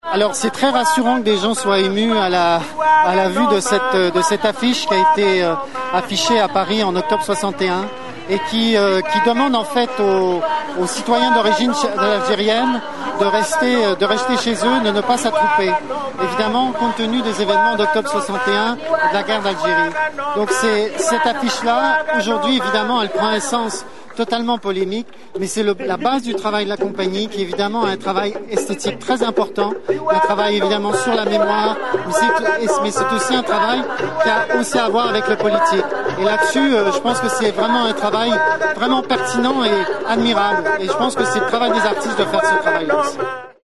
Deux jours plus tard, il récidive au micro de France Bleu Cotentin : « C’est très rassurant que des gens se soient émus à la vue de cette affiche.